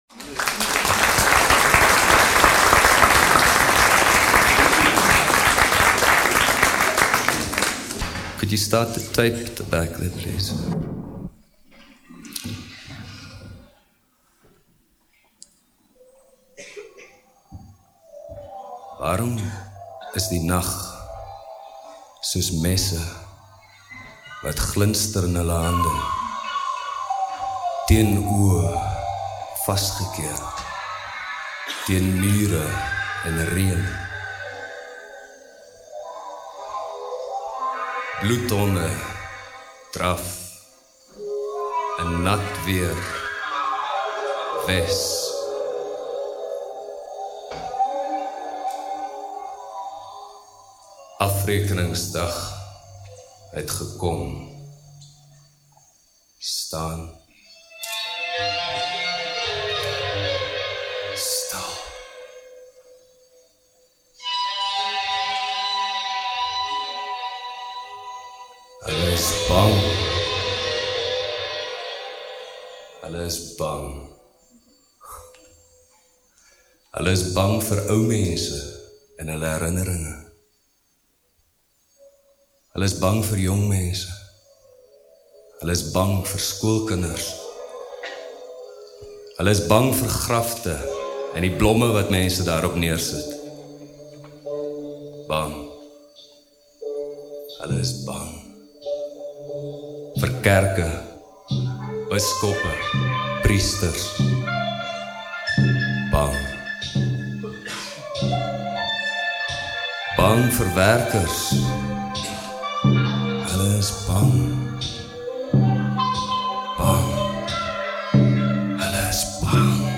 recorded 1989
stereo